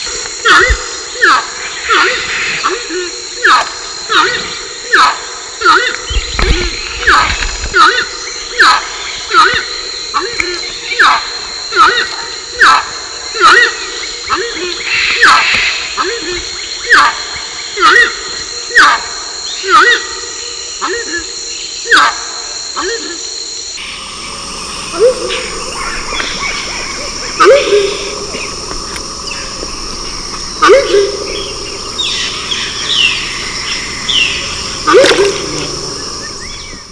Anhima cornuta - Aruco.wav